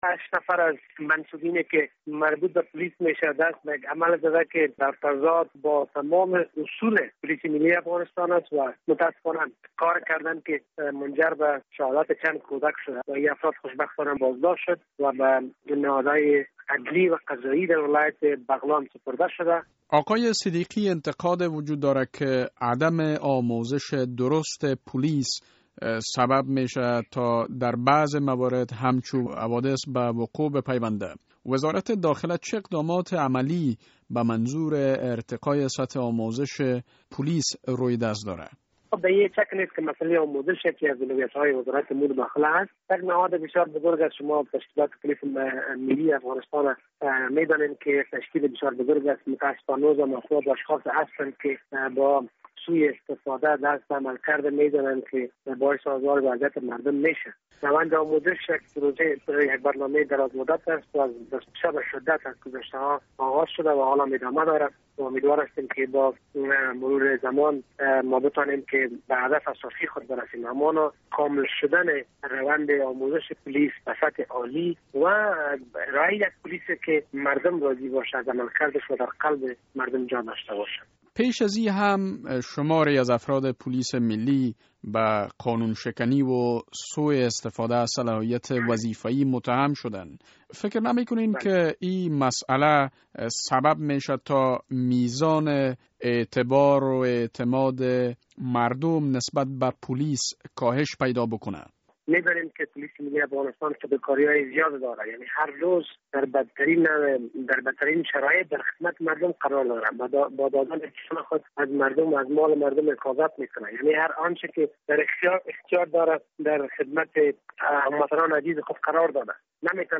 مصاحبه در مورد سپردن 8 پولیس به لوی څارنوالی به اتهام قتل 6 کودک